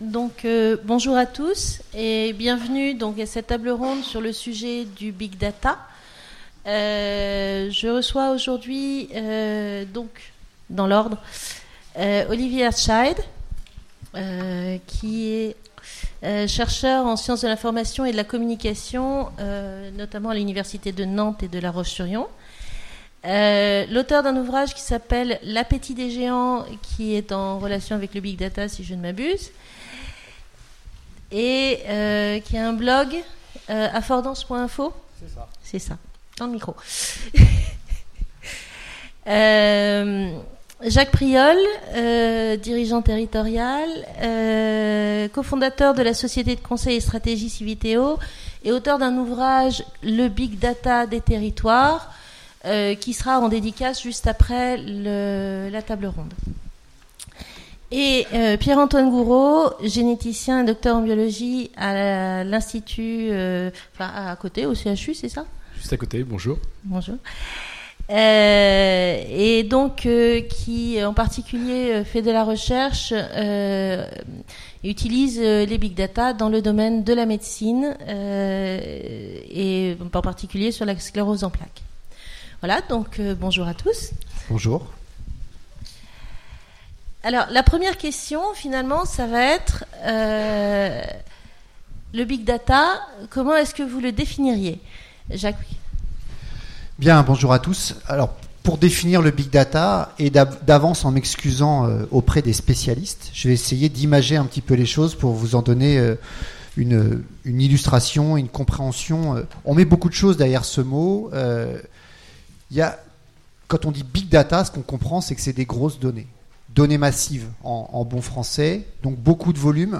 Utopiales 2017 : Conférence Big data